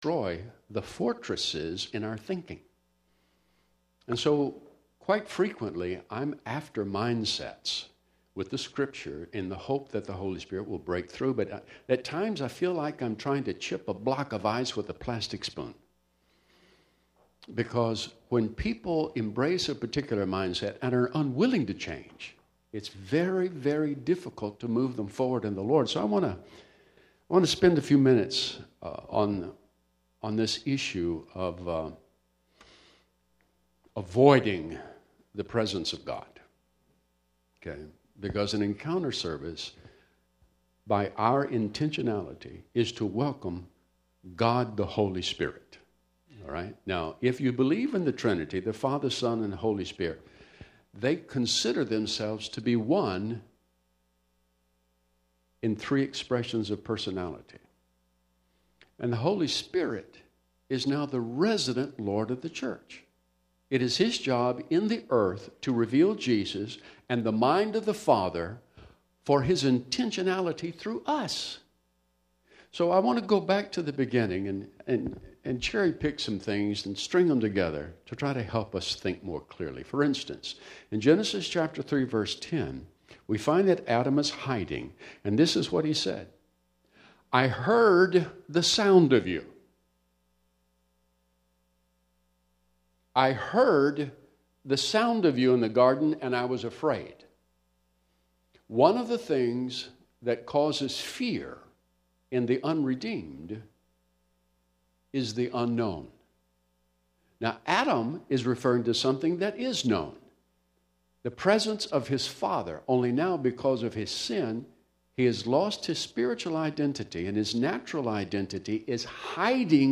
Encounter Service